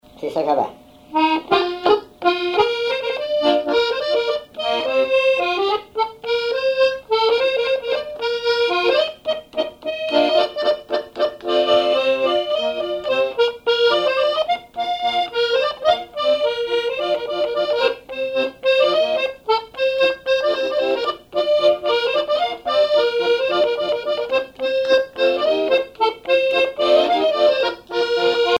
accordéon(s), accordéoniste
danse : java
Répertoire à l'accordéon chromatique
Pièce musicale inédite